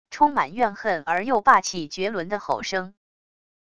充满怨恨而又霸气绝伦的吼声wav音频